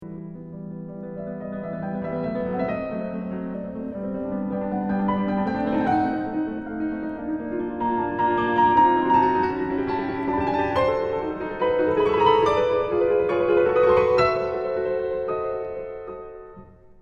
I have to apologize for the recording here, I haven’t gotten to the studio yet. So this is actually recorded at home, in my practicing studio. And as you can hear, I have practiced some, the piano is very out of tune.
They create some kind of rolling feeling that almost makes you disorientedly dizzy.